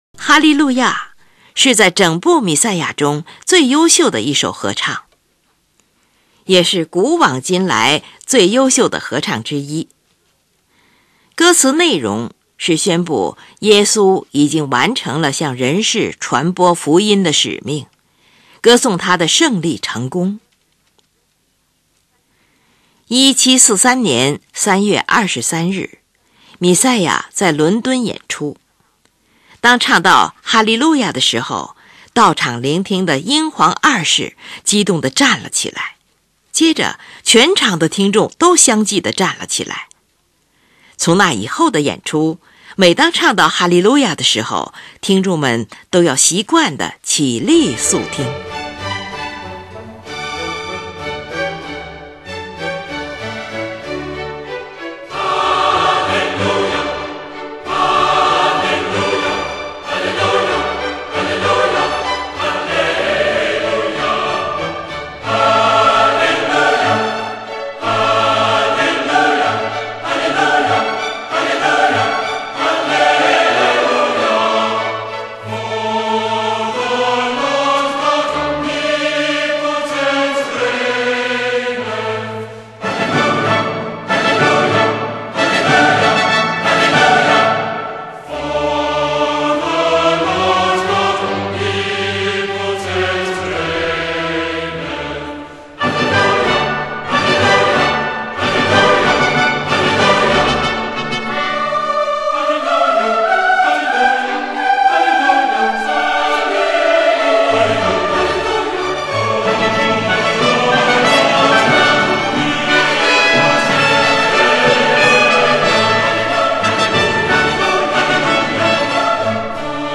它的旋律崇高雄浑、和声谐美丰富，节奏鲜明、铿锵有力。
全剧为主调和声音乐风格，以旋律优美、和声洗练见长。